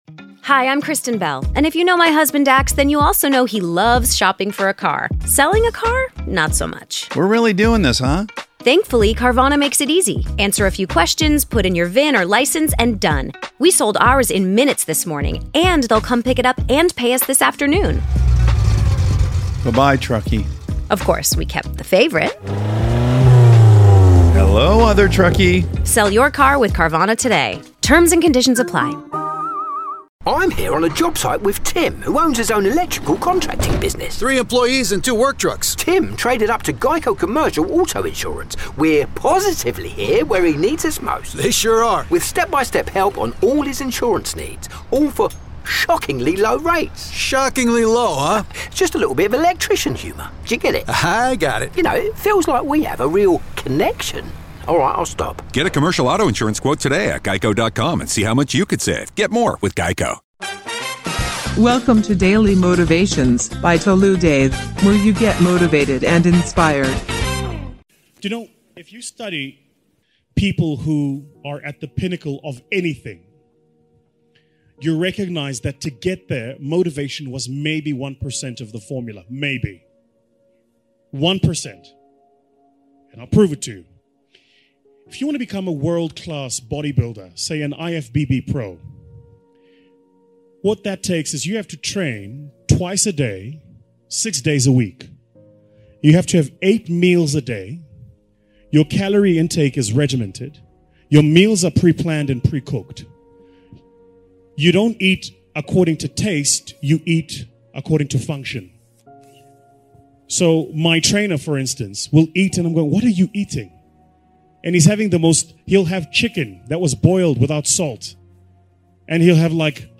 speaker: Jim Rohn